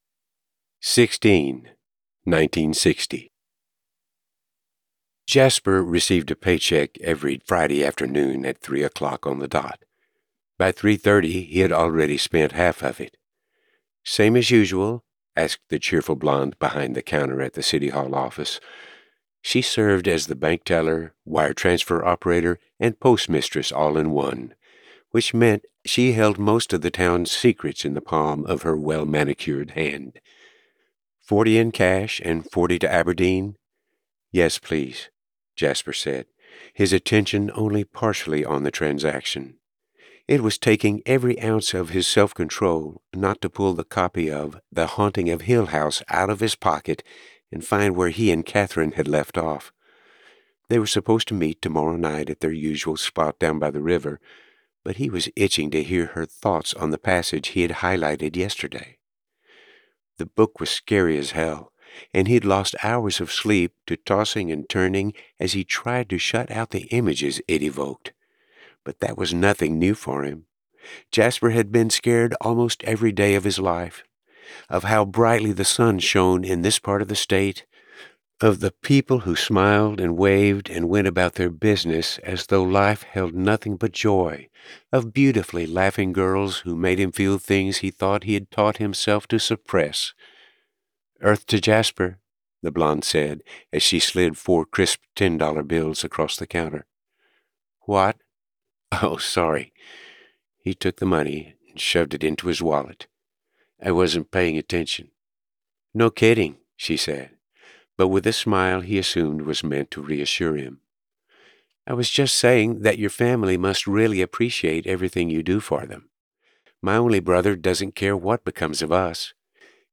The-Library-of-Borrowed-Hearts-by-Lucy-Gilmore_general-fiction_sample.mp3